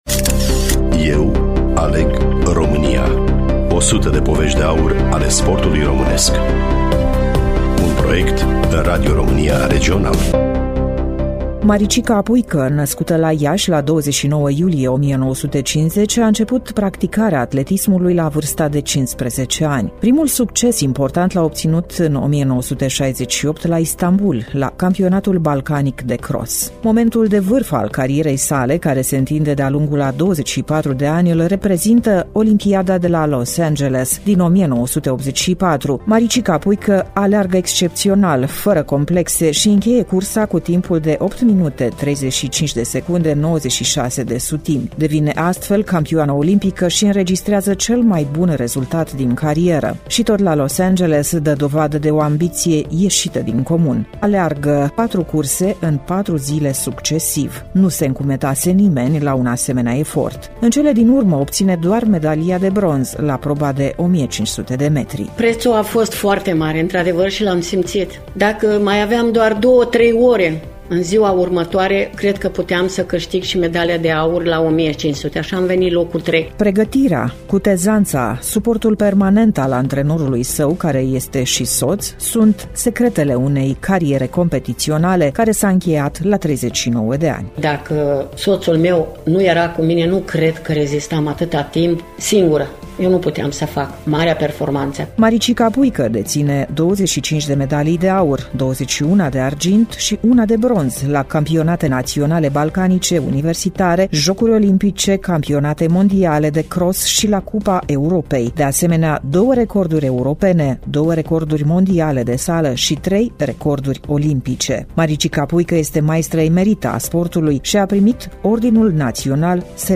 Studioul: Radio Romania Iaşi